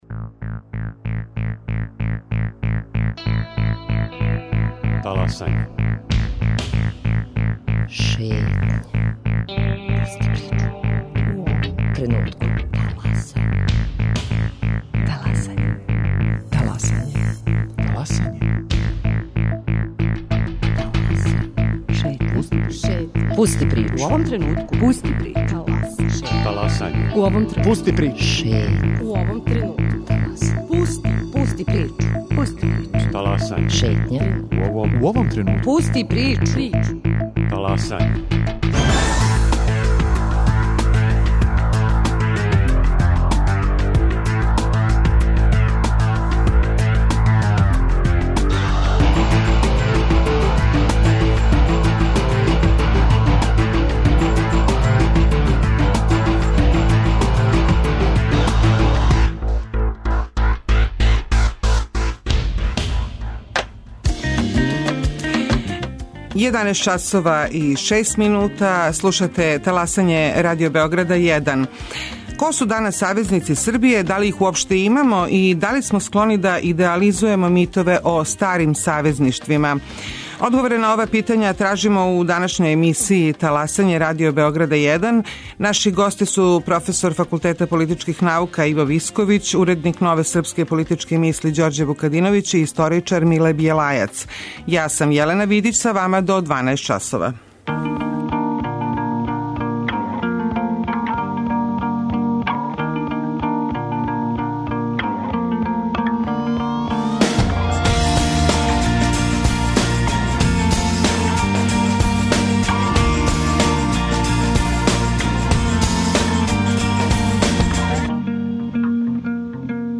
Ко су савезници Србије? Постоје ли у 21-вом веку искрена савезништва међу државама и народима? Гости емисије су: професор Факултета политичких наука